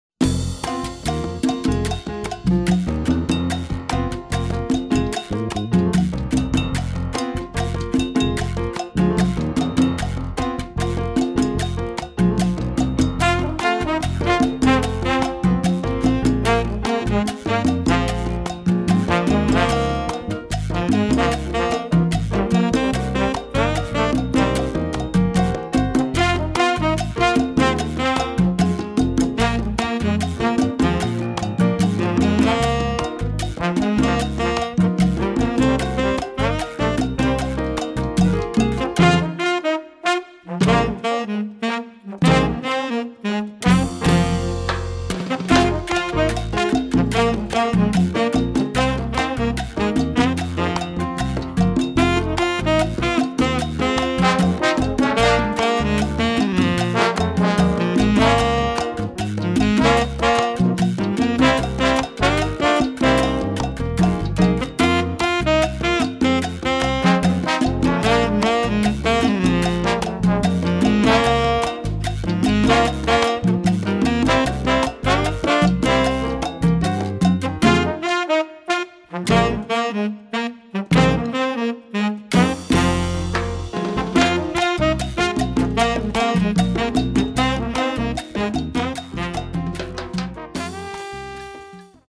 Category: combo (sextet)
Style: mambo
Solos: open
Instrumentation: combo (sextet) tenor, trombone, rhythm (4)